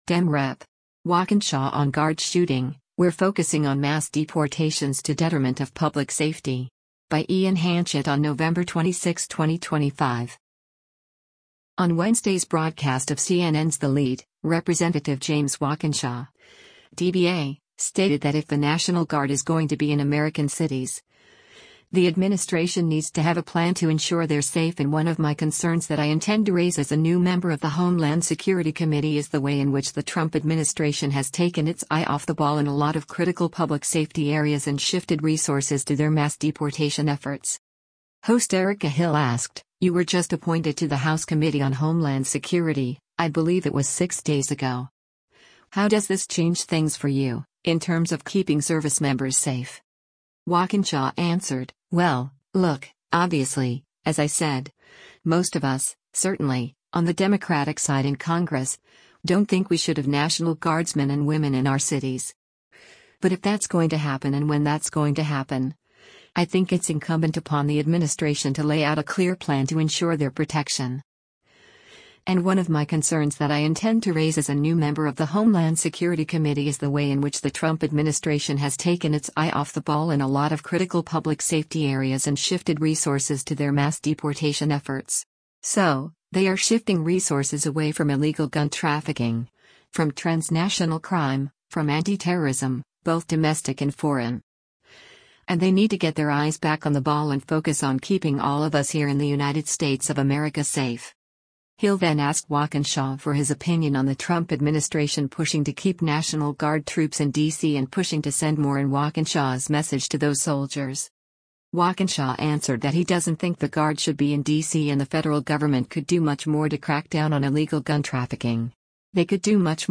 On Wednesday’s broadcast of CNN’s “The Lead,” Rep. James Walkinshaw (D-VA) stated that if the National Guard is going to be in American cities, the administration needs to have a plan to ensure they’re safe “And one of my concerns that I intend to raise as a new member of the Homeland Security Committee is the way in which the Trump administration has taken its eye off the ball in a lot of critical public safety areas and shifted resources to their mass deportation efforts.”